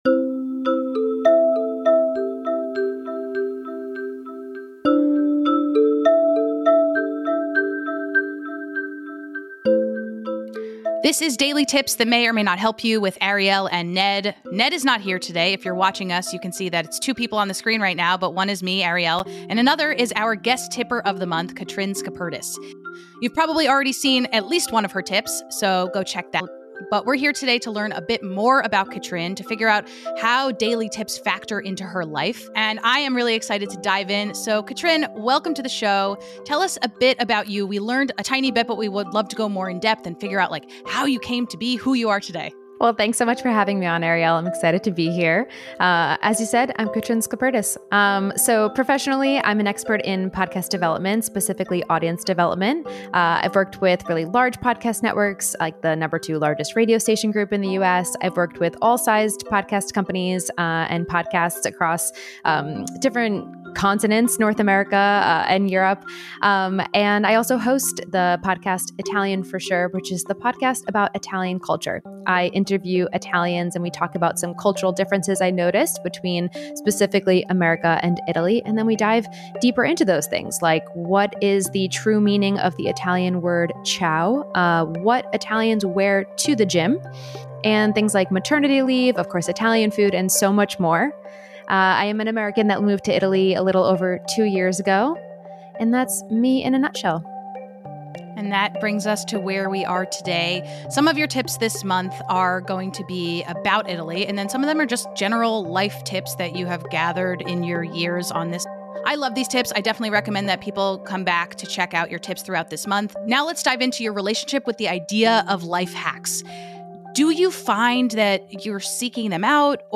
Whether you’re an aspiring podcaster, interested in Italian culture, or just looking for ways to bring more balance into your life, this conversation is packed with actionable tips and refreshing perspectives.